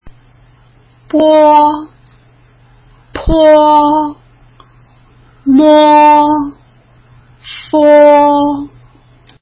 唇音
b(o) (無気音)唇を軽くあわせて、弱い息で｢ボォー｣と発音するイメージ。
p(o) (有気音)唇を軽くあわせて、強い息で｢ポー｣と発音するイメージ。
m(o) 唇をあわせて、ゆっくりと口をあけながら、息を鼻から抜くように｢モー｣と発音するイメージ。
f(o) 上の前歯に下唇をあてて、唇をすぼめながら｢フォー｣と発音するイメージ。